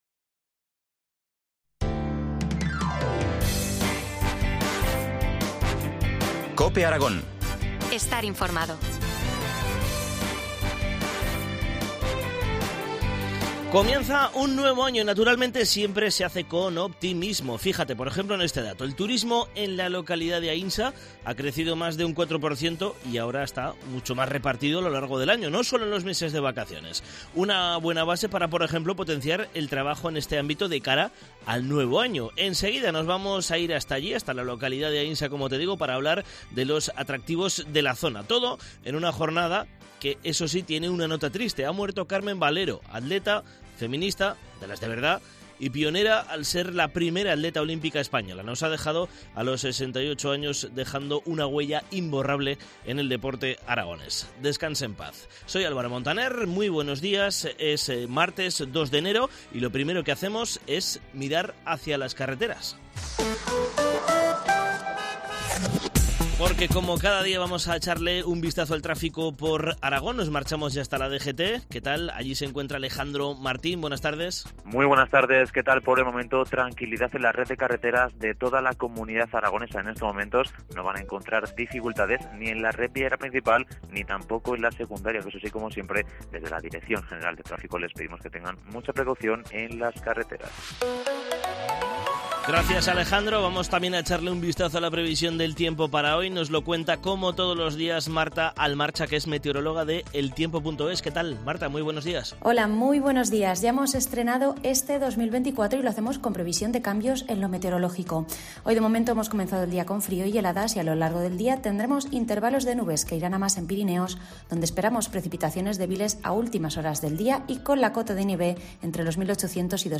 AUDIO: Entrevista del día en COPE Aragón